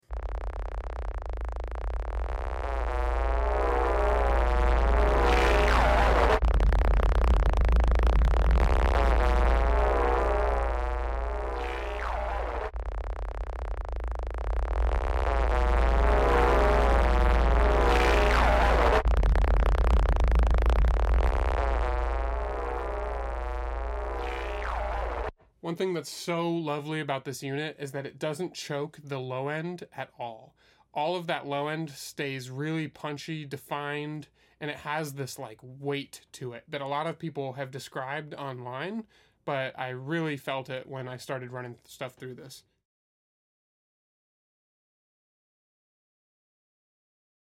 💥 A clip, from the full YouTube video, showing the mind bending saturation and distortion that you can bring forth from the Audioscape Golden 58